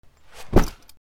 ジーンズを落とす 布団
/ J｜フォーリー(布ずれ・動作) / J-05 ｜布ずれ
『バサ』